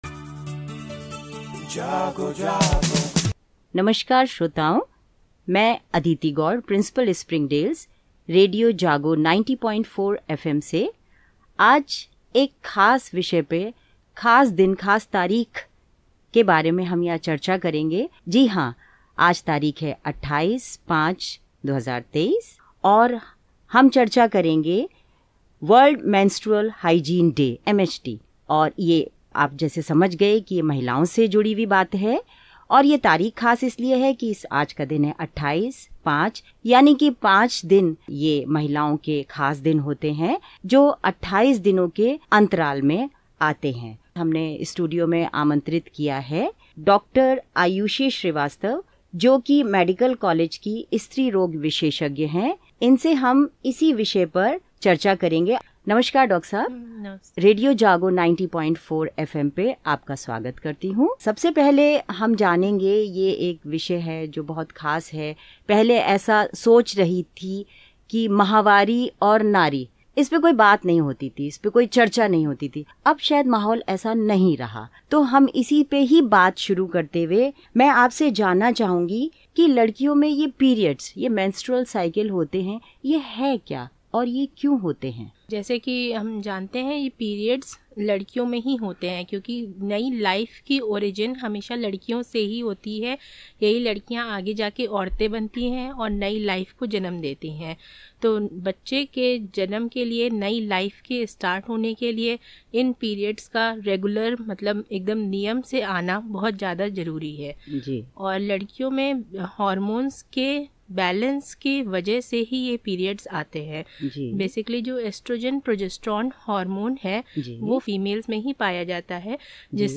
interviews
on Community Radio, Radio JaaGo 90.4 FM, Hardoi, Uttar Pradesh